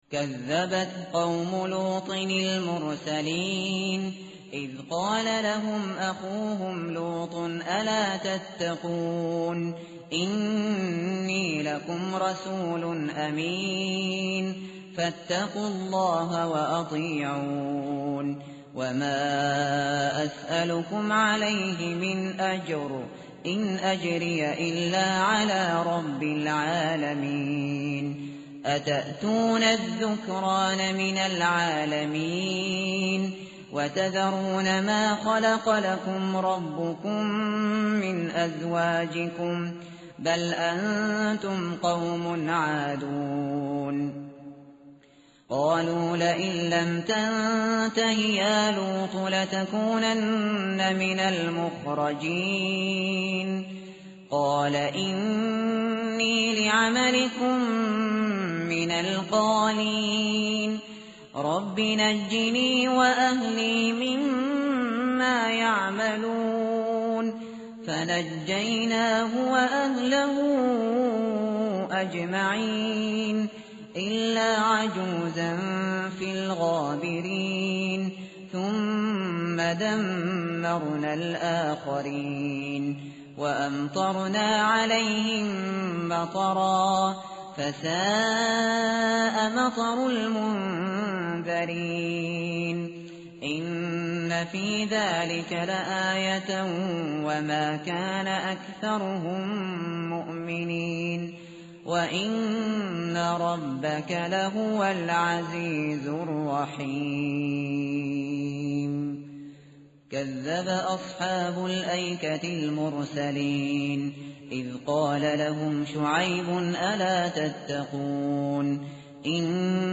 متن قرآن همراه باتلاوت قرآن و ترجمه
tartil_shateri_page_374.mp3